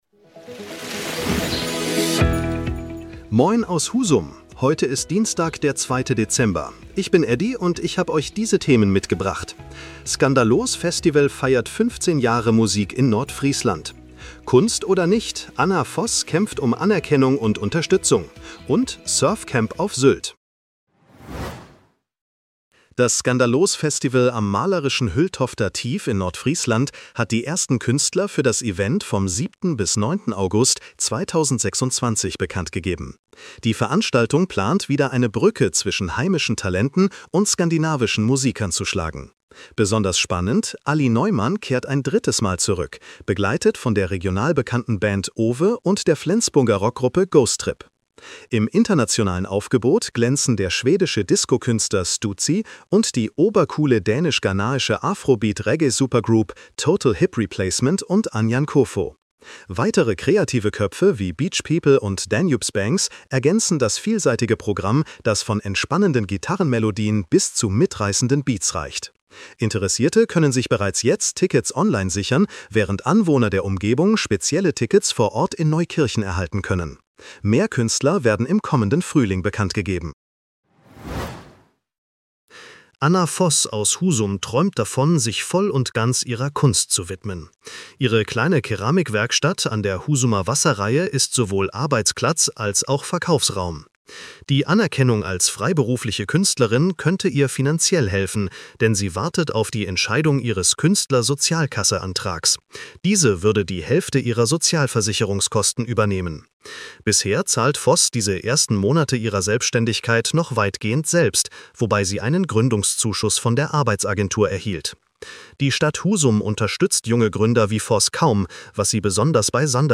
Deine täglichen Nachrichten
Nachrichten